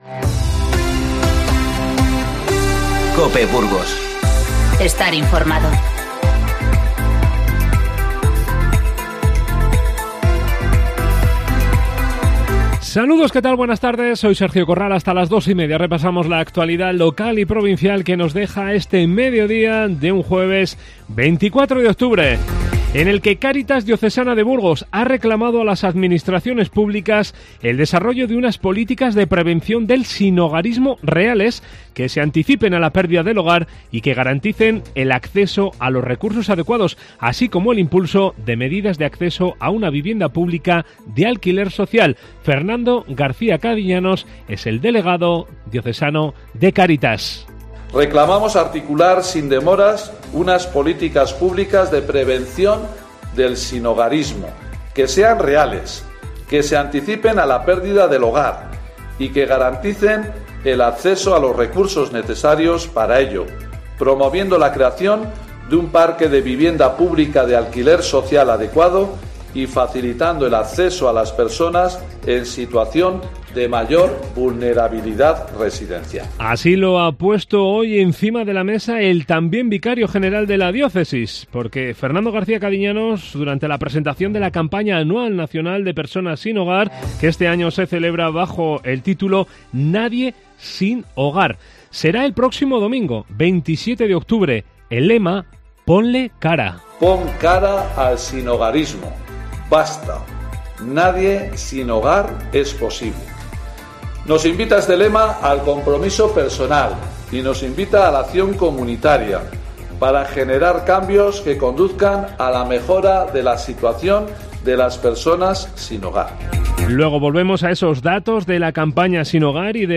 INFORMATIVO Mediodía 24-10-19